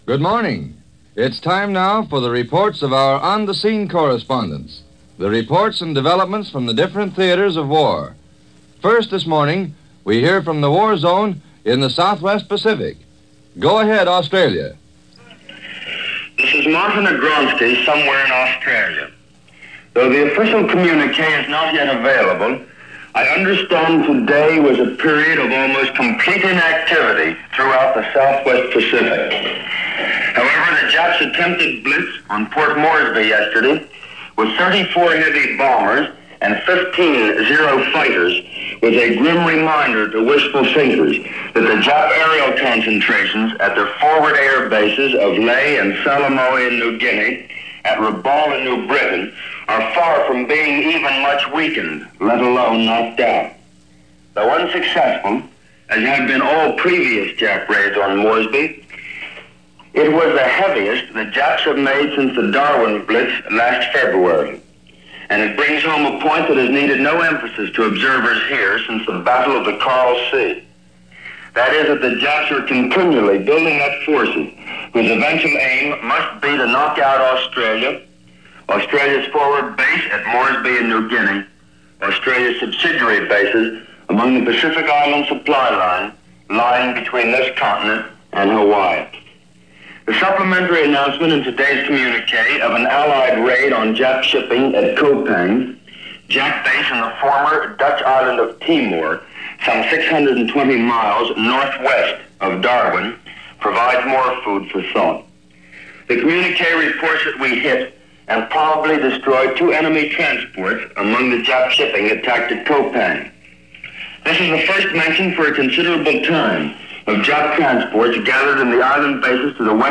May 19, 1942 – News Of The World – NBC – Gordon Skene Sound Collection –